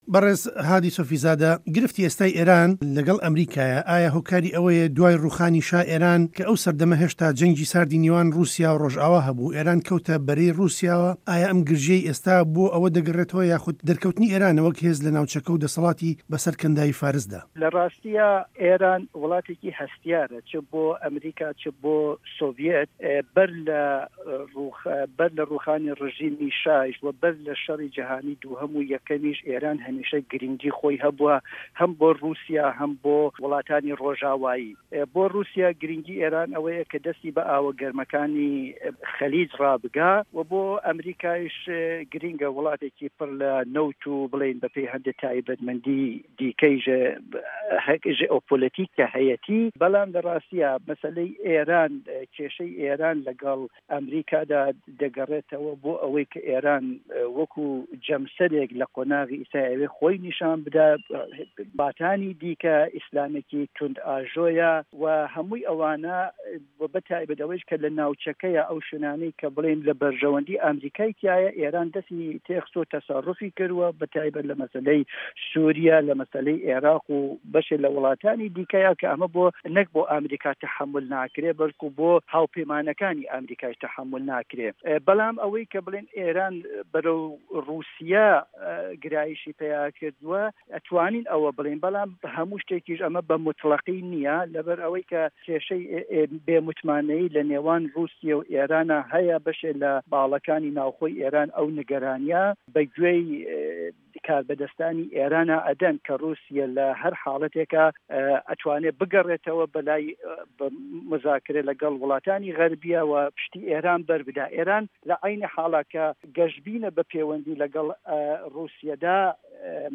گفتووگۆ